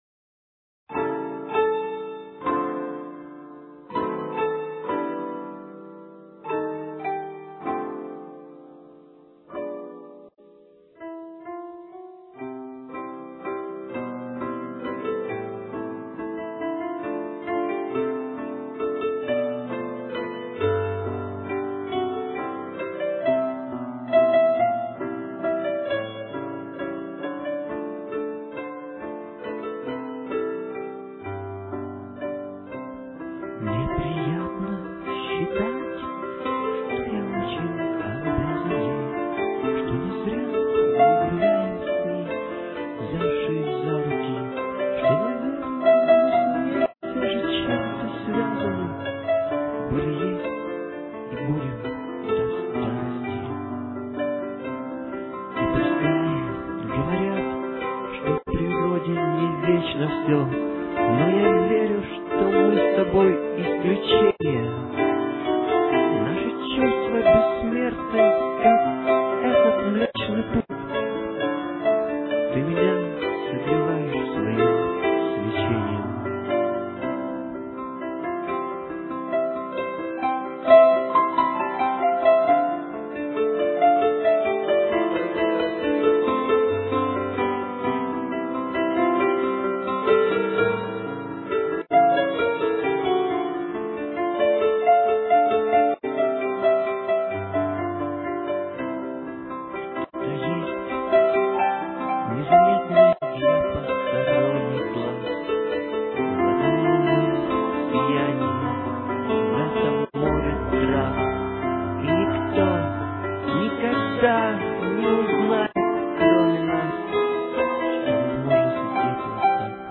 with piano